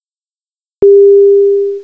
gear-ding.wav